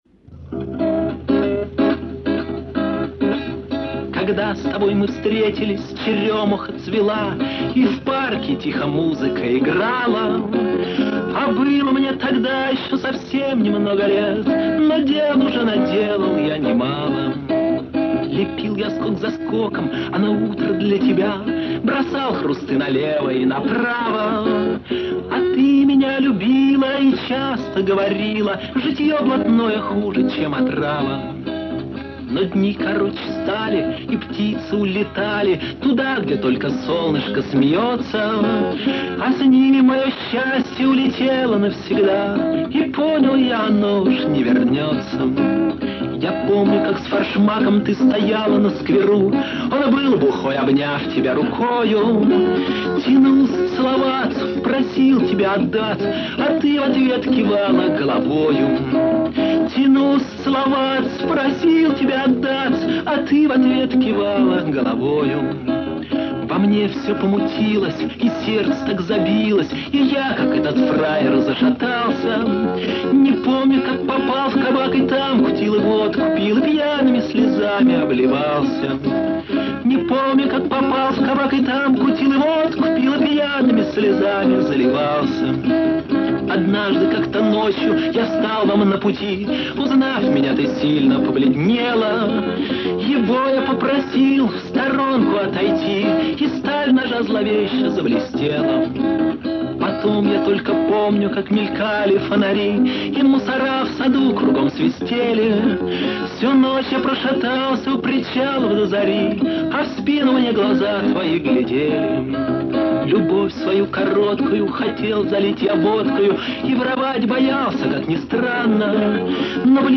Домашняя Запись